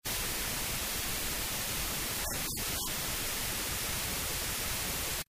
Cris-hibou-des-marais-2.mp3